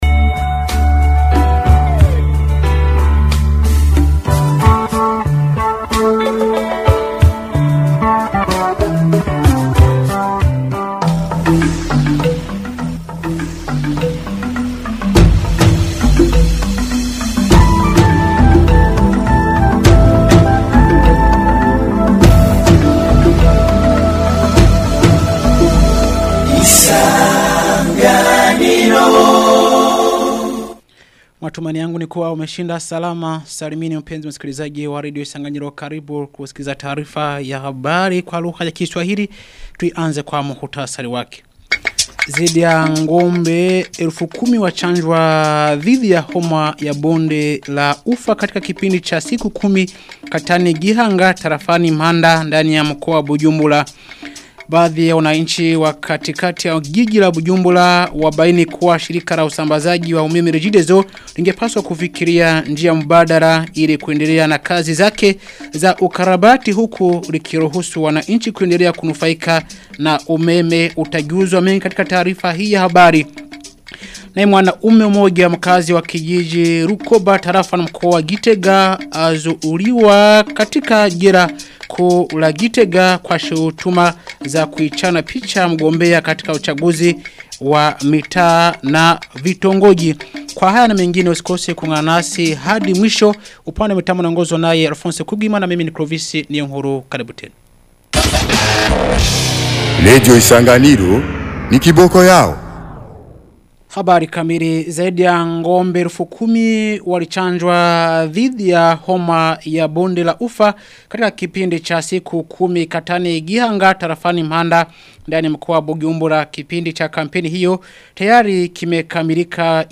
Taarifa ya habari ya tarehe 11 Agosti 2025